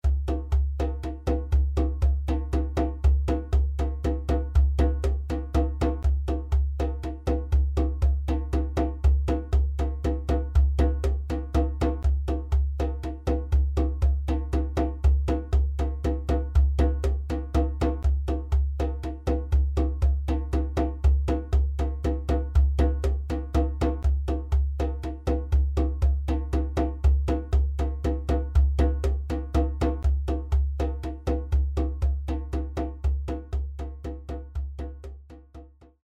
djembé1.mp3